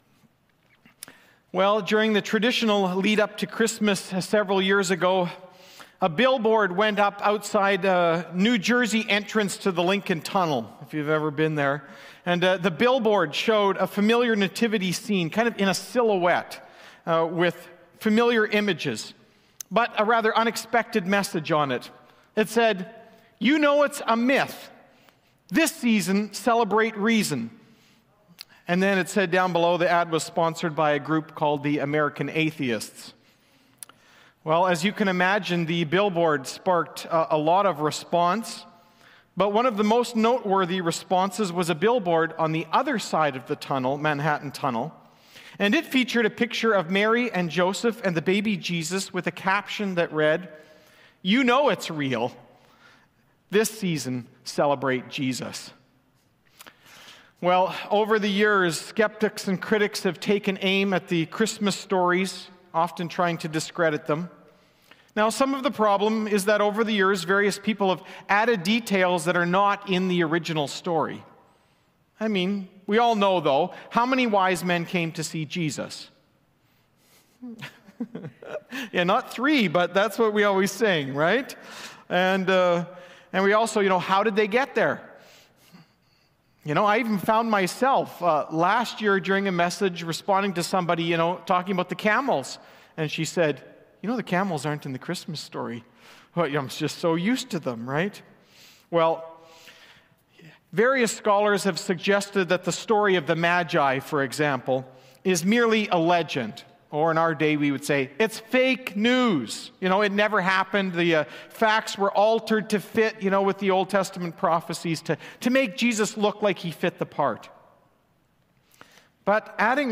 Sermons | Eagle Ridge Bible Fellowship